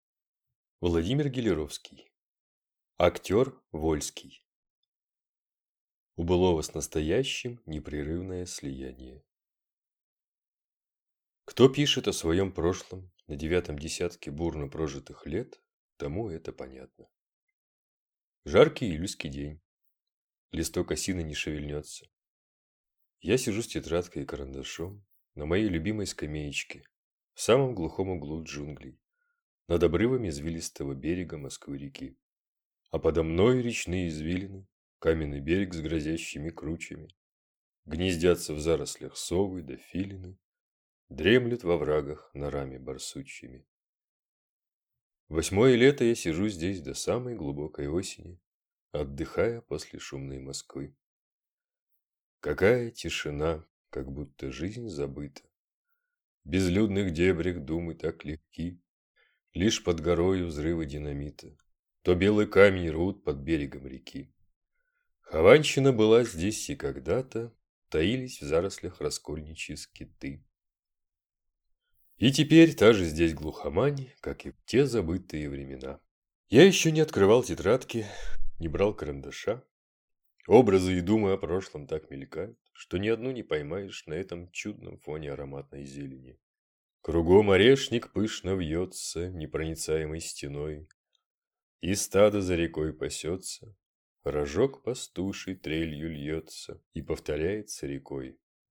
Аудиокнига Актер Вольский | Библиотека аудиокниг